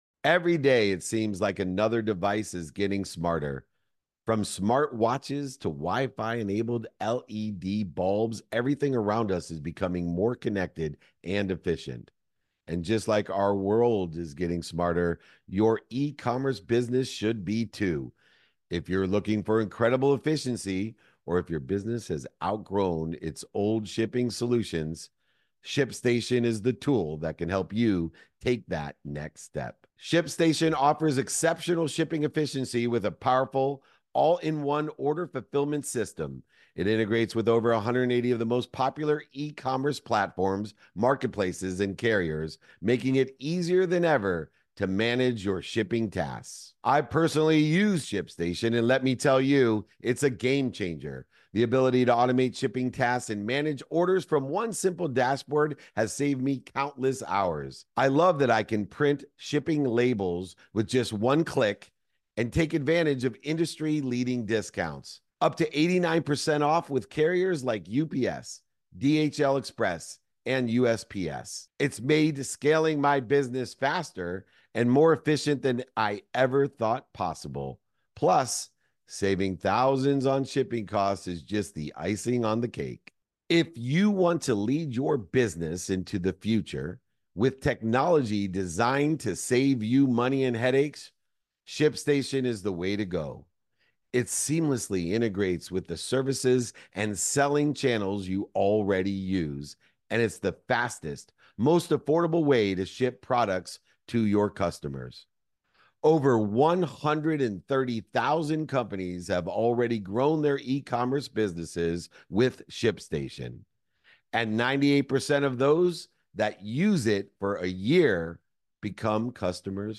In today’s episode, I’m joined by Olympic champion LaShawn Merritt, a sprinter who mastered the 400 meters and now sets his sights on becoming one of the world's best speakers. LaShawn shares his journey of transitioning from being the best on the track to embracing the challenge of a new stage. He discusses the importance of falling in love with the process, focusing on daily discipline, and finding joy in every step, both in sports and in life.